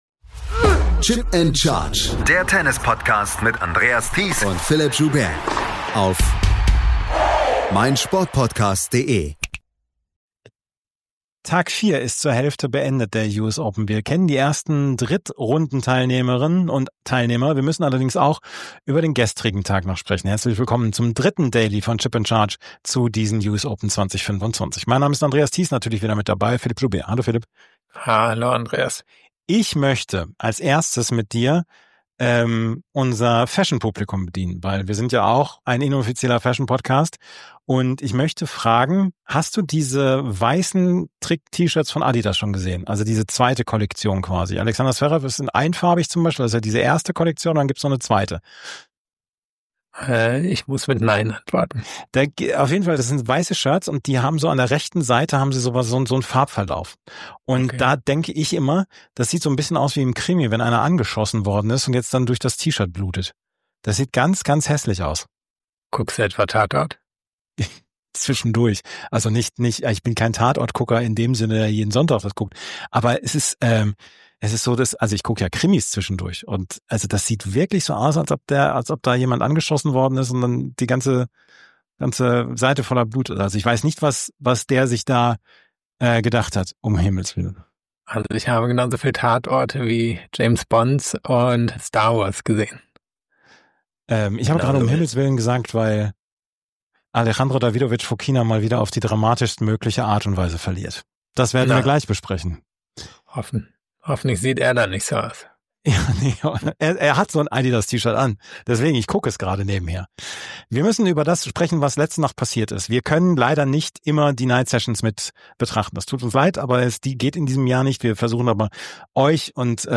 In der letzten Woche fand ein kombiniertes ITF-Frauen- und ATP-Challenger-Turnier beim Hamburger Tennisverband statt. Die Challenger Corner war vor Ort und wir präsentieren Interviews von vor Ort.